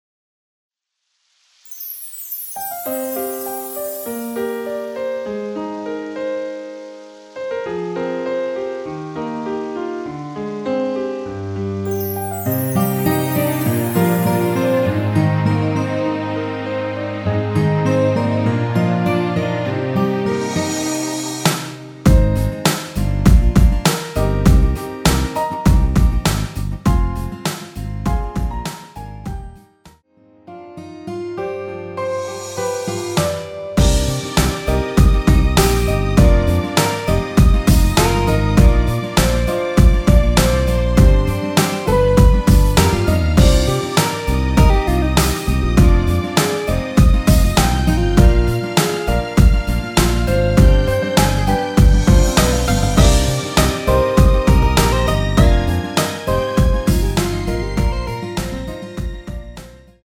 MR 입니다.
Cm
앞부분30초, 뒷부분30초씩 편집해서 올려 드리고 있습니다.
중간에 음이 끈어지고 다시 나오는 이유는